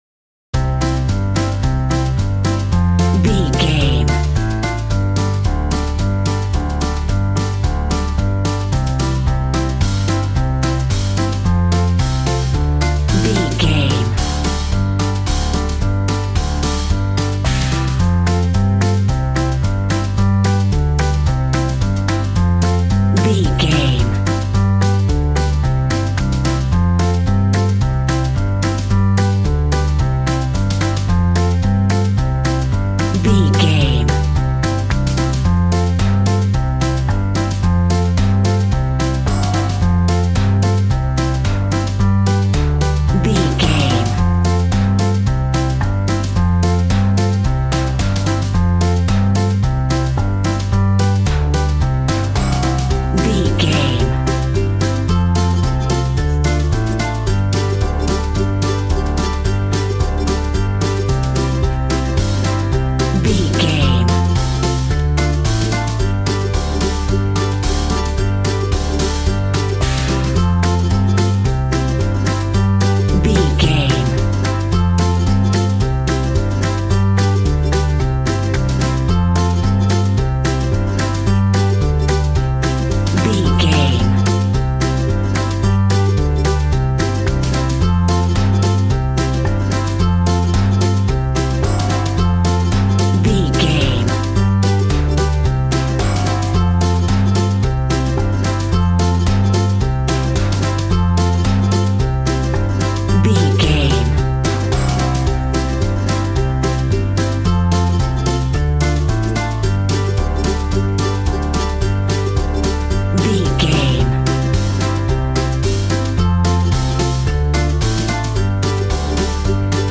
Upbeat, uptempo and exciting!
Ionian/Major
cheerful/happy
bouncy
electric piano
electric guitar
drum machine